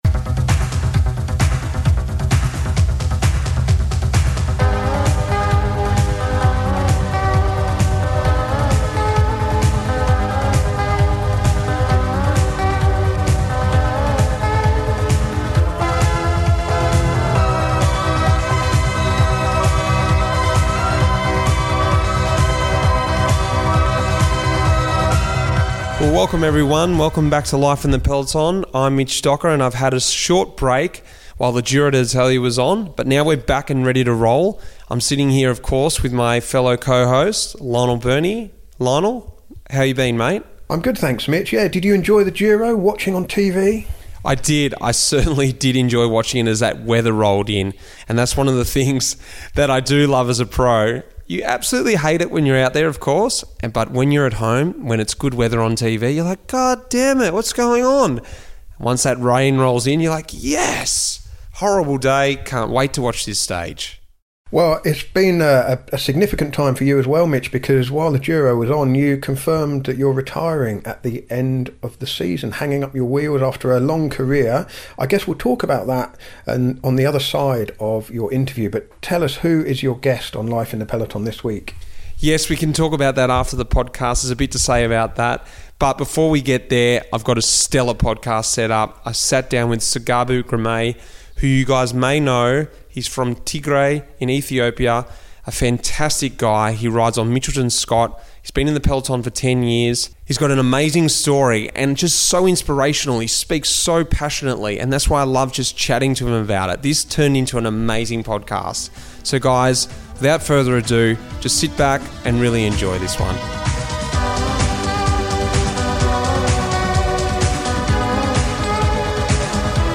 Life in the Peloton is back after taking a break while the Giro d'Italia was on, which gave me a chance to sit down with someone I've wanted to get on the podcast for a long time.